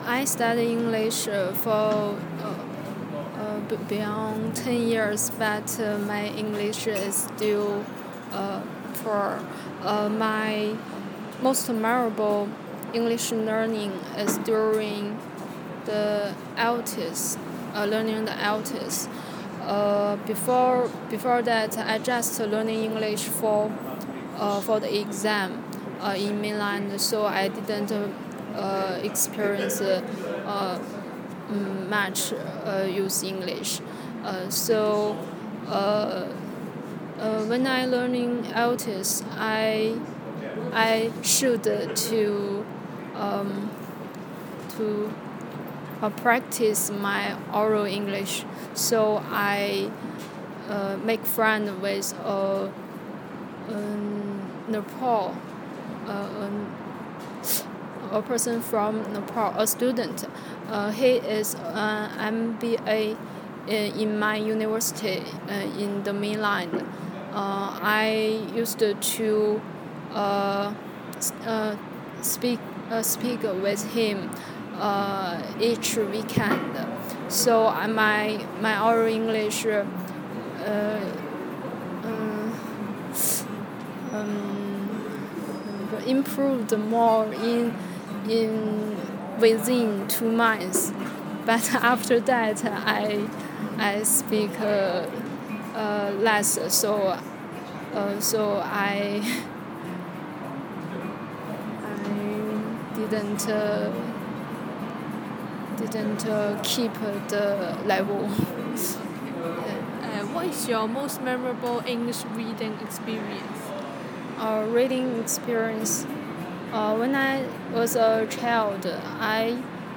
Subcategory: Achievement, Reading, Self-help, Speech